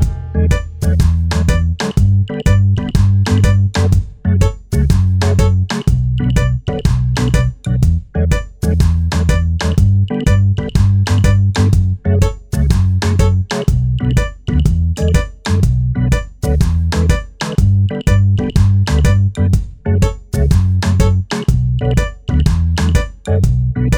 minus guitars no Backing Vocals Reggae 3:32 Buy £1.50